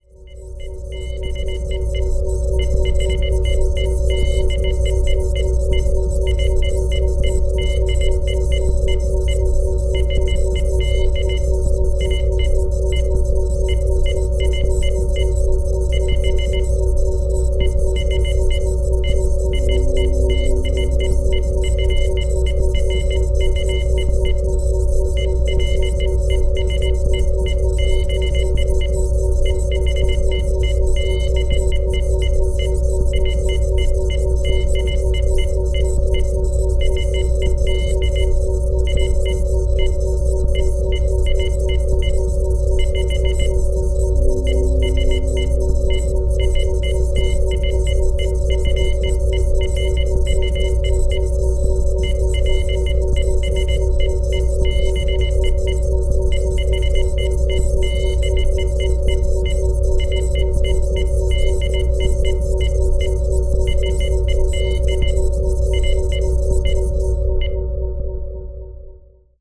Фоновый звук музыки программиста